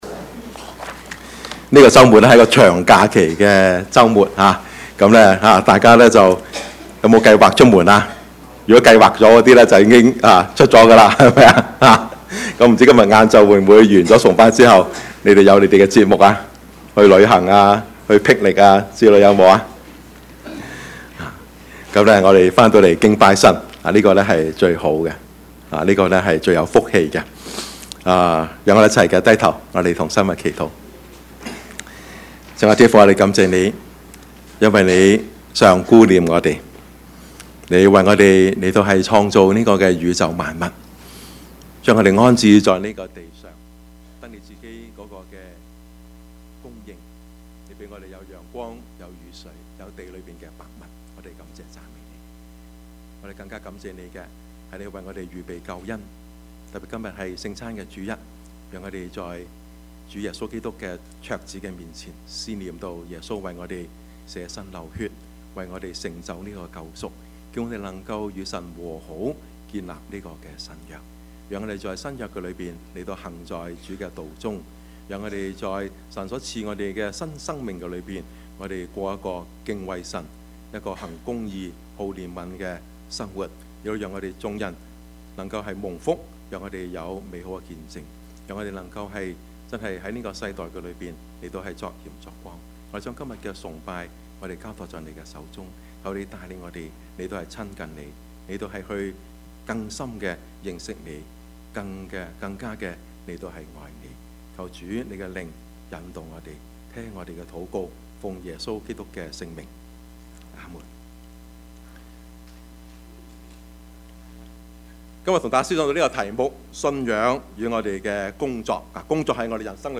Service Type: 主日崇拜
Topics: 主日證道 « 智慧人與愚昧人 屬靈的爭戰 »